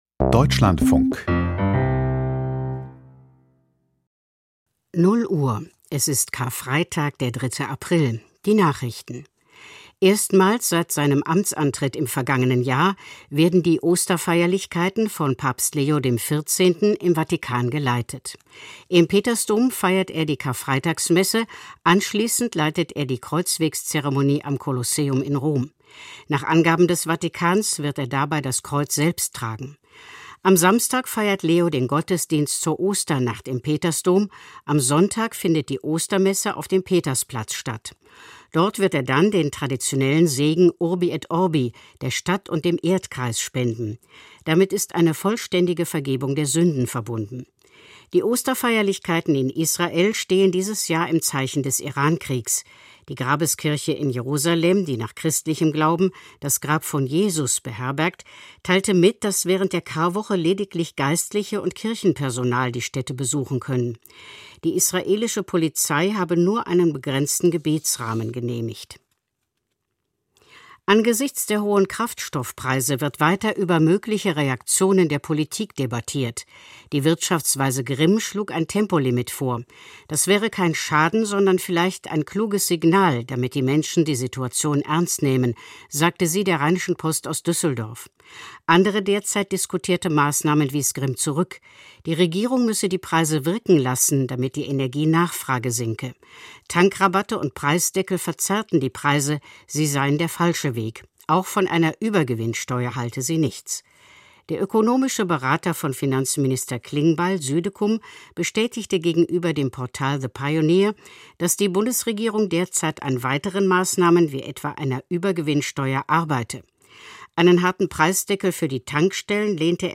Die Nachrichten vom 03.04.2026, 00:00 Uhr